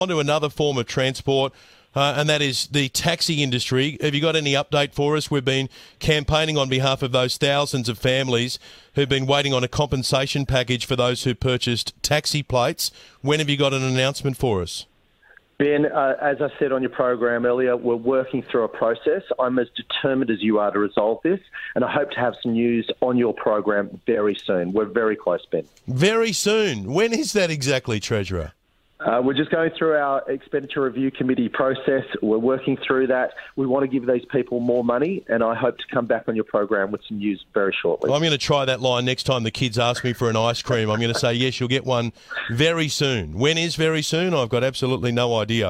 The NSW Treasurer provided an update on fair and proper compensation for all Taxi Licence Owners in NSW on the Ben Fordham Show on 2GB Radio this morning.